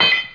00323_Sound_potlid1.aif